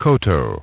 Транскрипция и произношение слова "koto" в британском и американском вариантах.